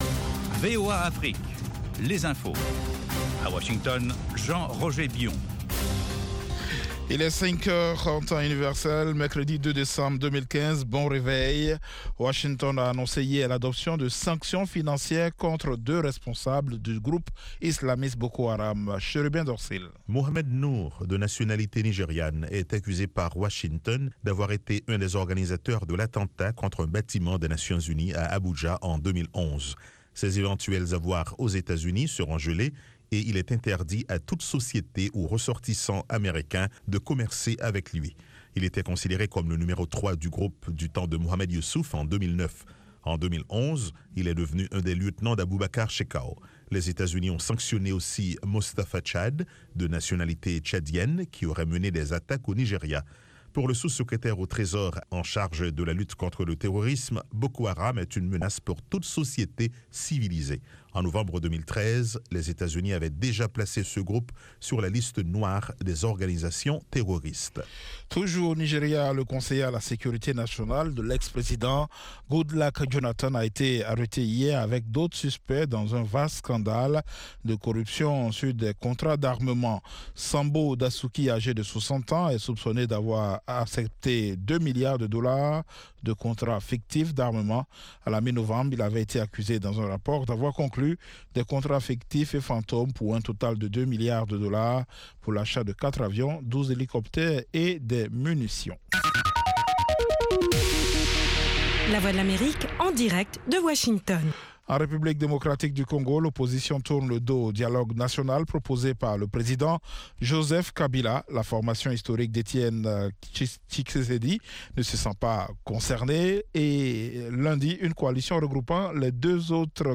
5min Newscast